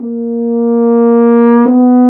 Index of /90_sSampleCDs/Roland L-CDX-03 Disk 2/BRS_F.Horn FX/BRS_Intervals
BRS F.HRN 01.wav